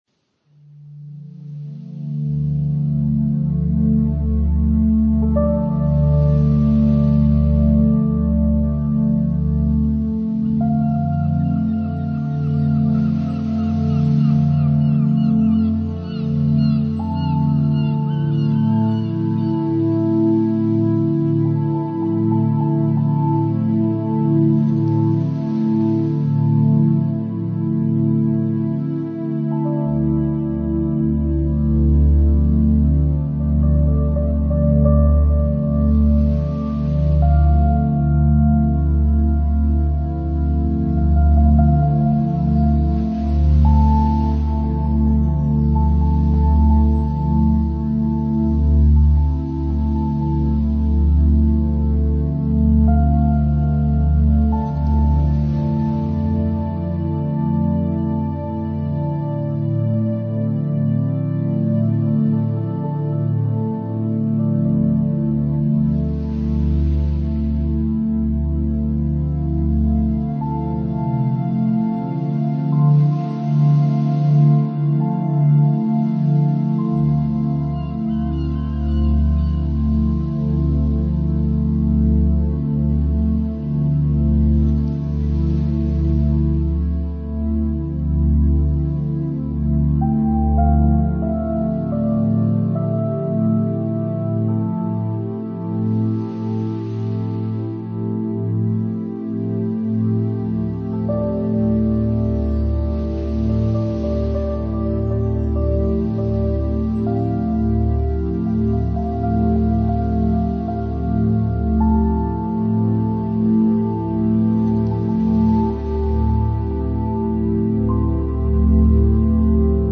nature sounds and atmospheric sounds, perfect for relaxation
ambient
new age